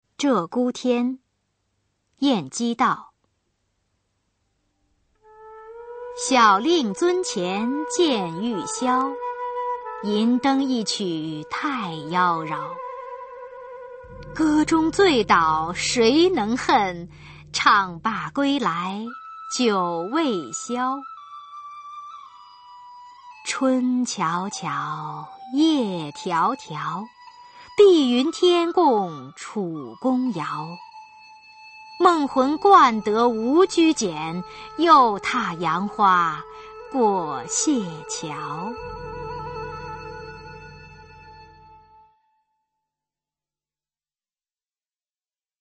[宋代诗词诵读]晏几道-鹧鸪天·小令 宋词朗诵